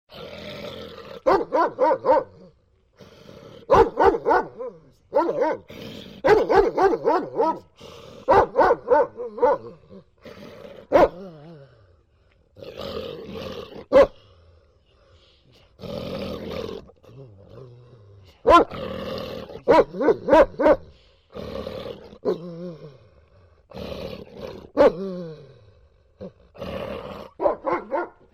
Bellender Hund klingelton kostenlos
Kategorien: Tierstimmen
Bellender-Hund.mp3